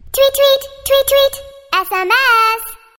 Category: Message Ringtones